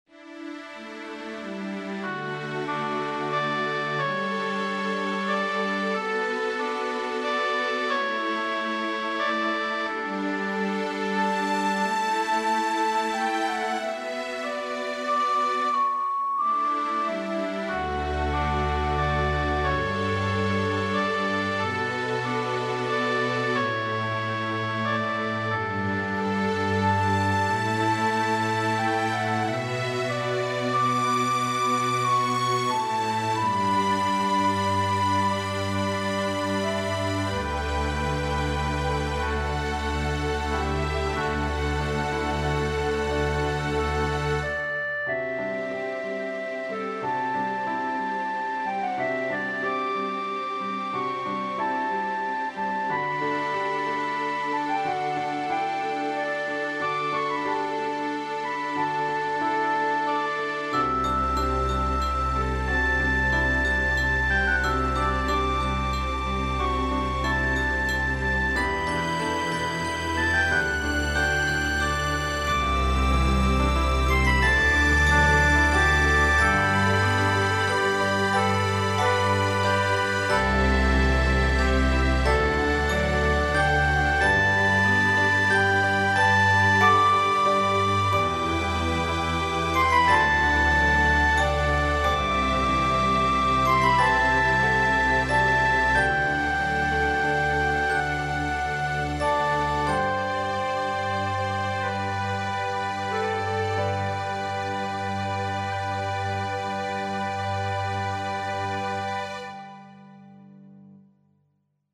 ゆったりとした曲。